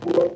BrokenWindows5.ogg